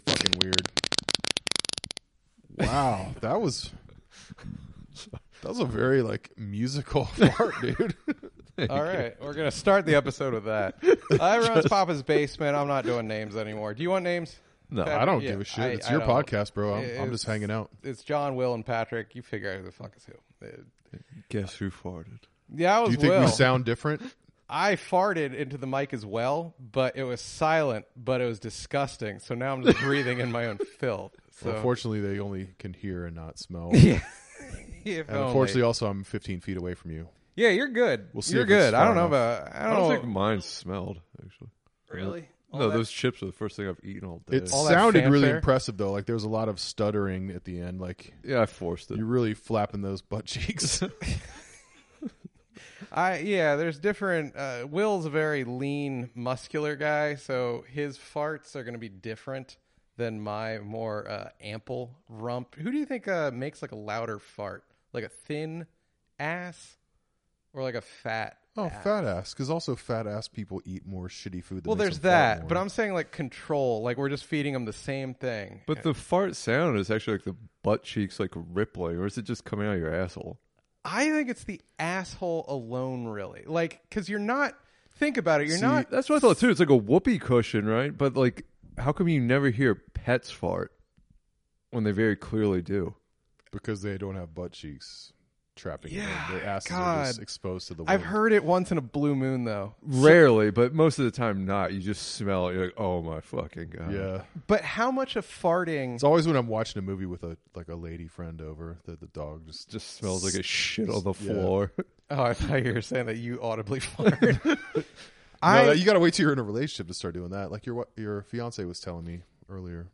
DC's Premier Comedy Podcast and Radio Show.